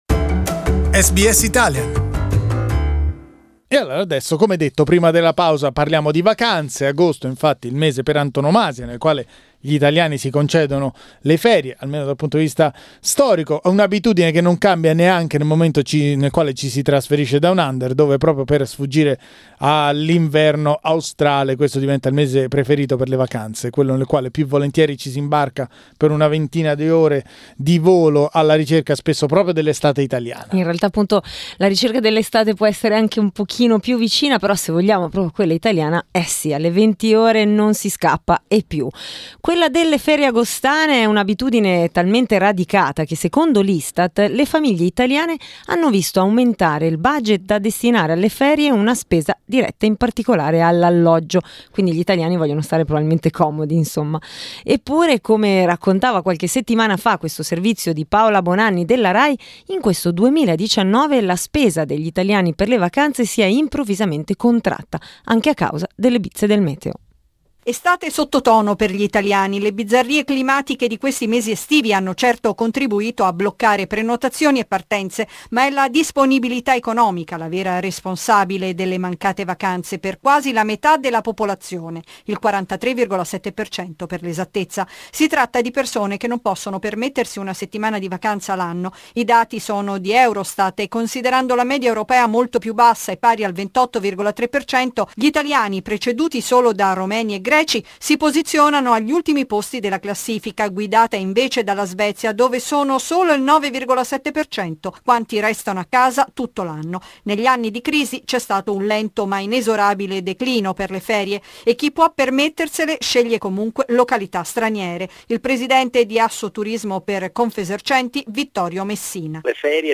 La parola agli ascoltatori e alle ascoltatrici per parlare del rapporto tra italiani e vacanze.